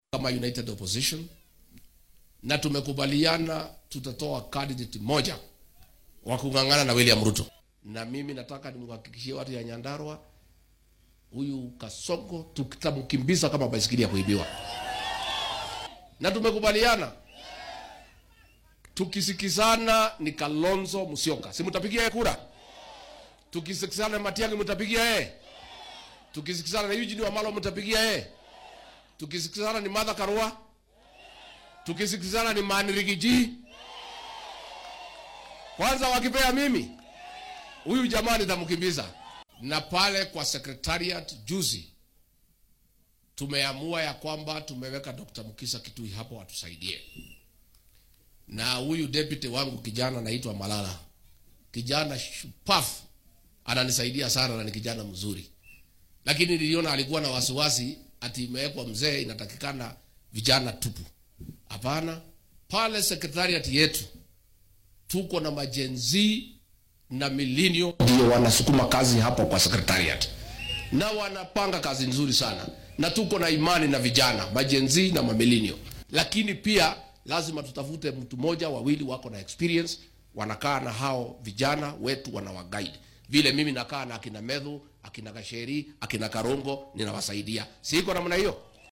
Ku xigeenkii hore ee madaxweynaha dalka Rigathi Gachagua ayaa shaaca ka qaaday in mucaaradka mideysan ay ku heshiyeen in ay soo saran hal musharax oo madaxtinimada dalka kula tartama madaxweyne William Ruto doorashada 2027-ka. Gachagua oo Axadda maanta ah ka hadlay ismaamulka Nyandarua ayaa sheegay in aan weli lagu heshiin qofka la tartami doono madaxweyaha balse ay ku heshiyeen oo kaliya in hal qof laga soo dhex saaro ku dhawaad 5 qof oo u hanqal taagaayo inay Ruto xilka ka badalaan.